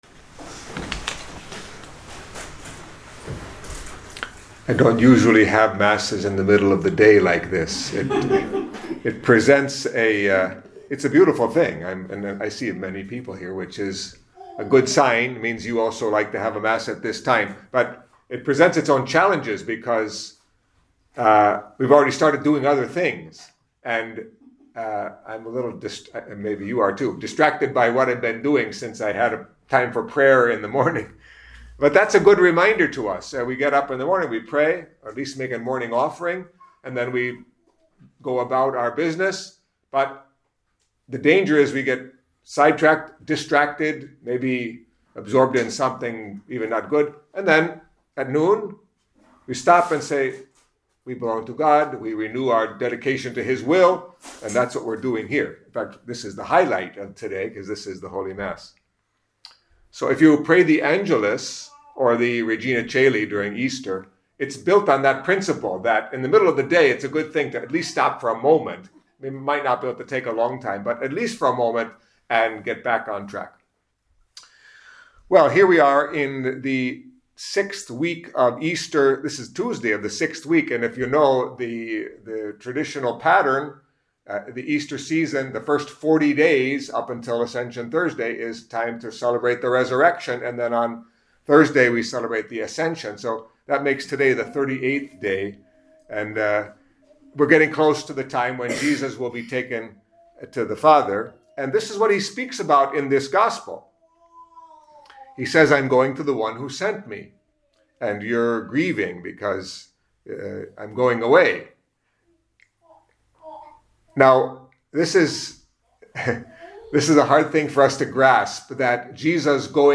Catholic Mass homily for Tuesday of the Sixth Week of Easter